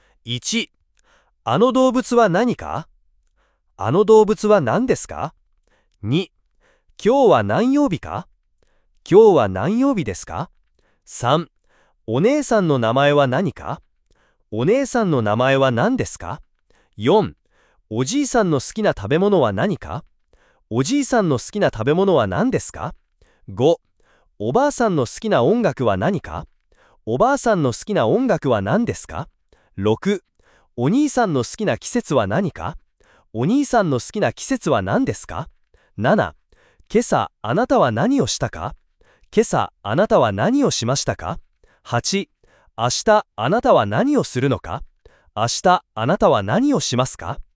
The grammatical rule is that it is always pronounced as なん
Otherwise, you always pronounce 何 as なに.